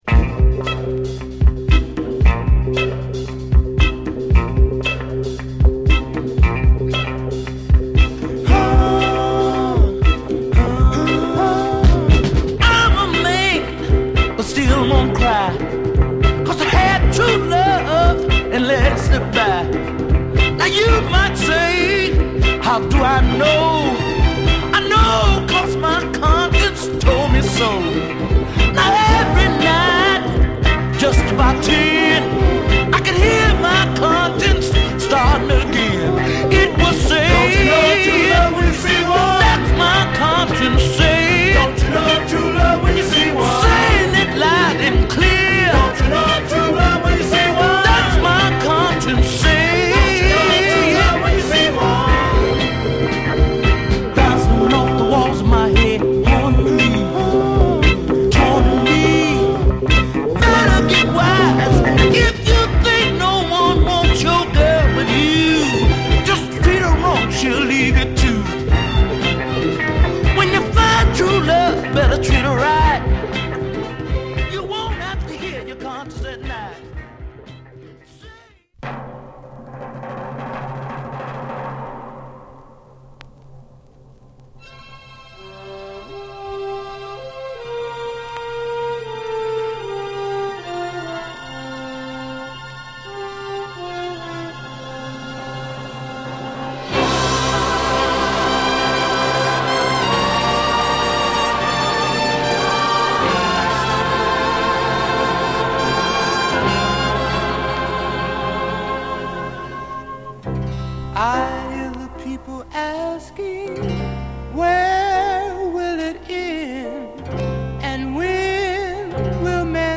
太いドラムとドライブ感溢れるカッティング・リフが牽引するスリリングなファンキーチューン！
壮大で華やかなアレンジが冴えたグルーヴィーな